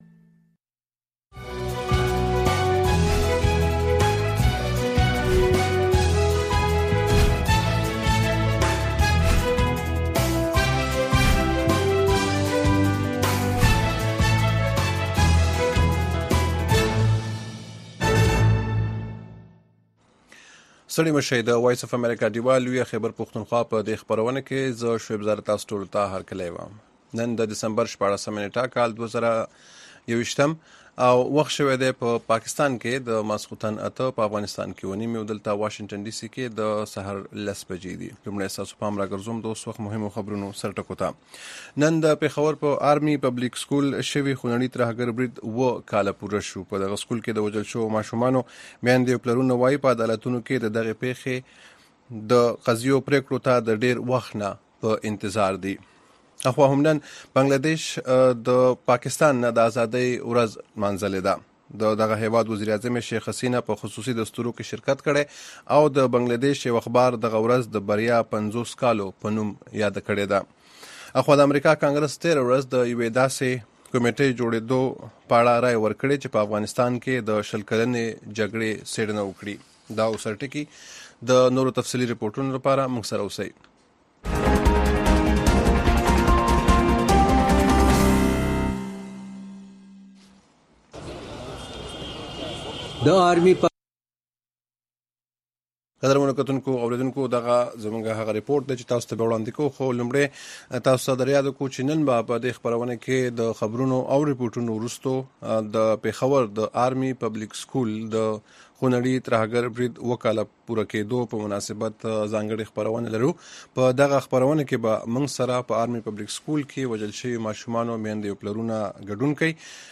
د وی او اې ډيوه راډيو خبرونه چالان کړئ اؤ د ورځې د مهمو تازه خبرونو سرليکونه واورئ.